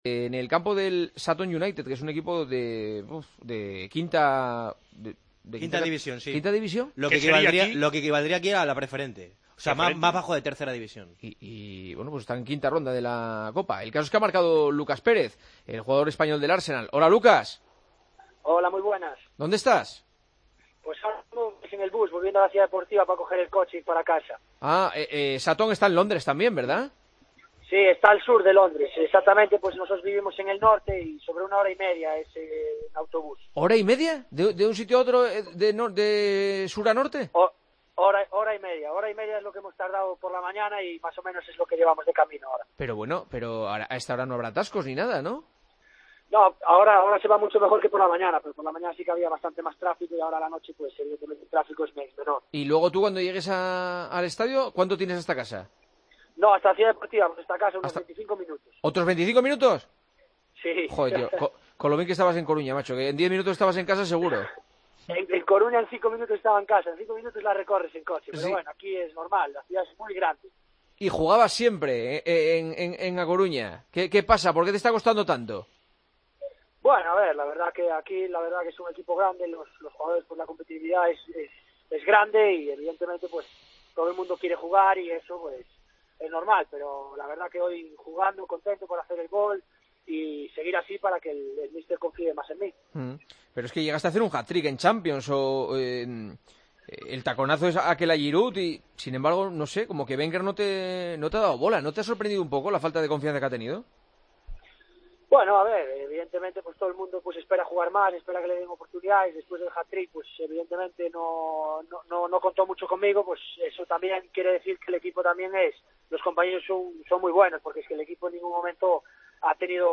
El Partidazo de COPE localizó al futbolista español del Arsenal en el autobús del equipo, tras jugar partido de la FA Cup ante el Sutton, de quinta división: "Después del hat-trick, Wenger no contó mucho conmigo, aunque los compañeros son muy buenos.